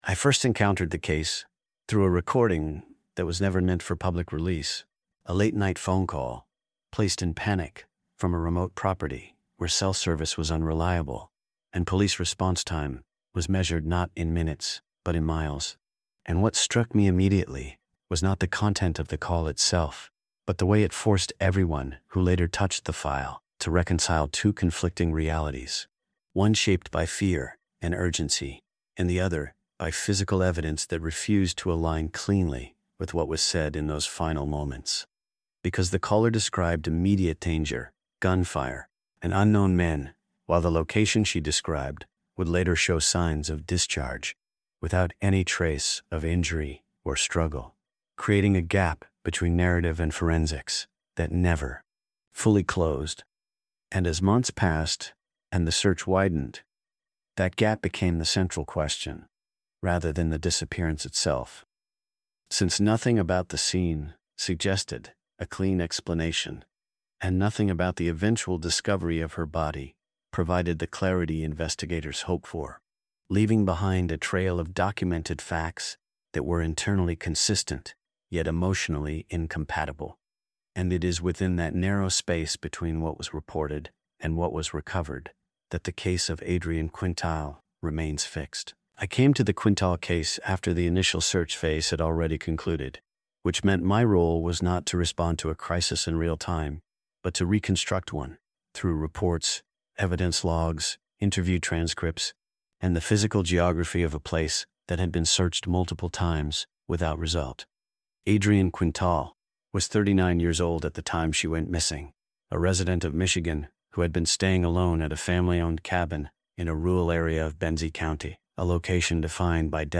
Told in a first-person investigative journalist voice